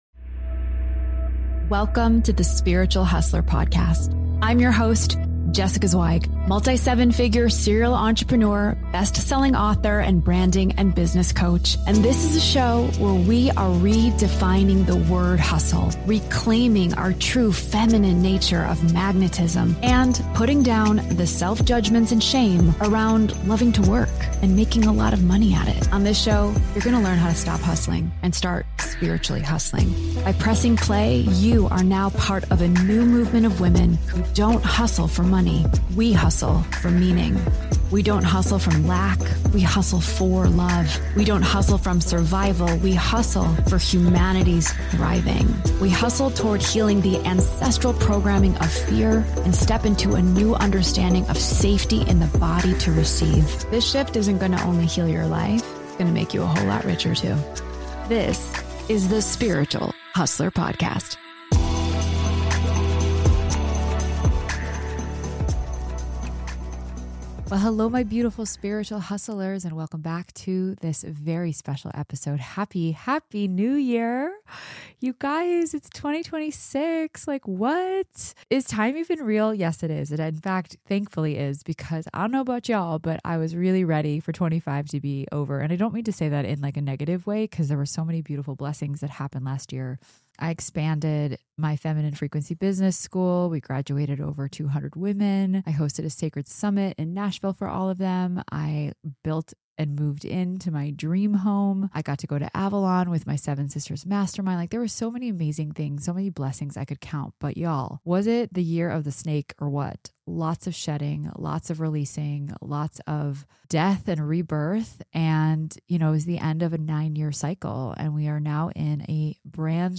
What unfolds is a raw, hilarious, and profoundly honest conversation about identity, friendship, control, spirituality, success, and the evolution required to step into a new cycle. This episode marks the energetic opening of 2026, a brand new studio and look for The Spiritual Hustler Podcast, and a new era of freedom, truth, and embodiment.